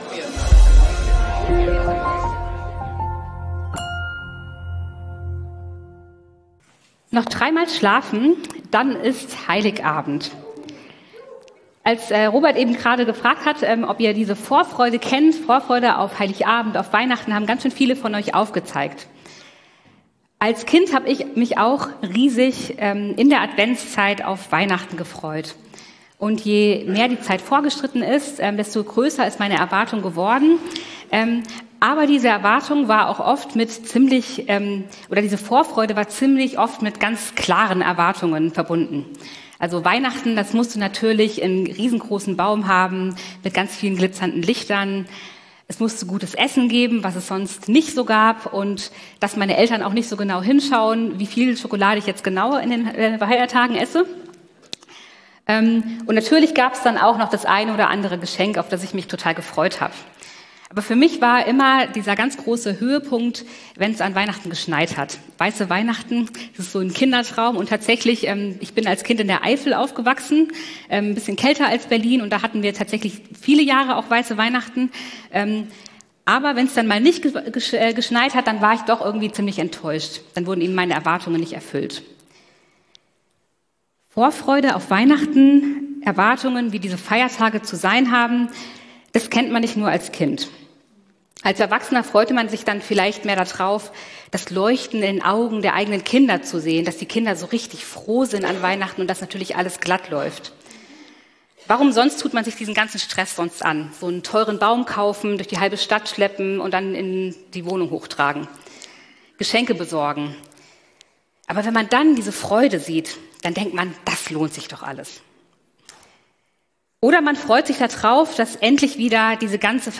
Hoffnung - zwischen Erwartung und Wirklichkeit ~ Predigten der LUKAS GEMEINDE Podcast